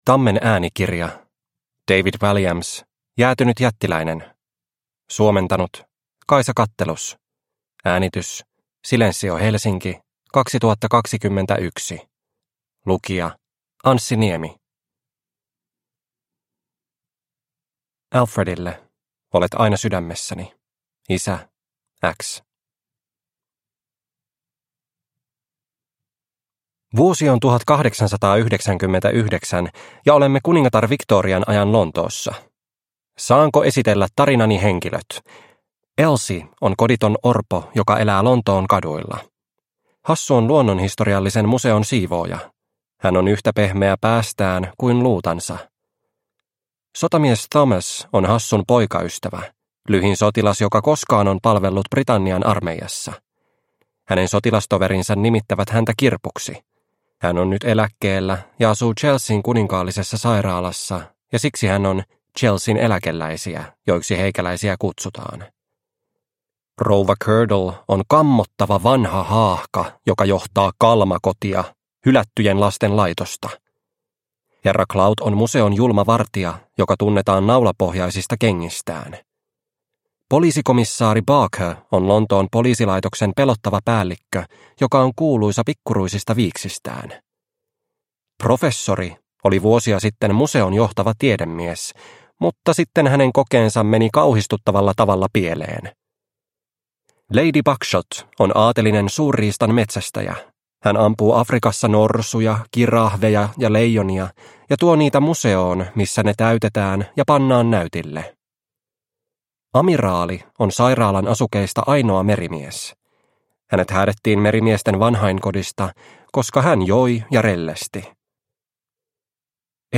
Jäätynyt jättiläinen – Ljudbok – Laddas ner